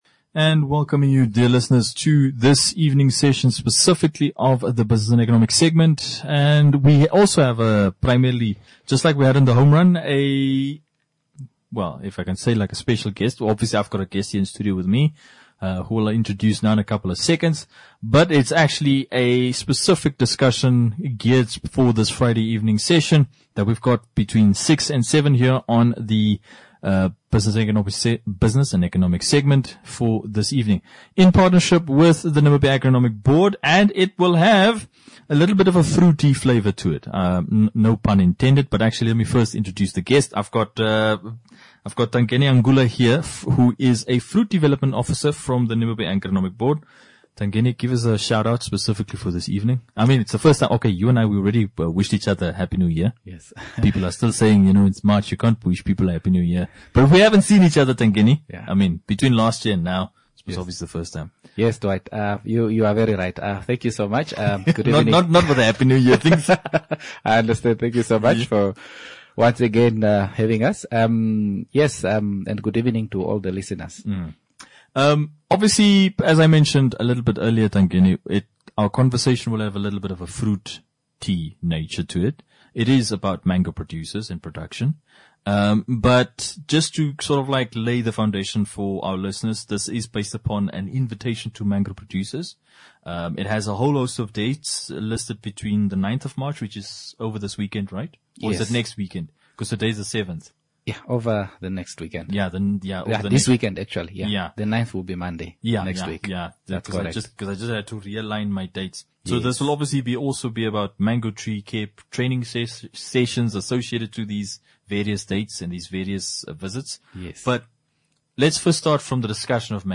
NAB ENGLISH INTERVIEW 6 MARCH 2026.mp3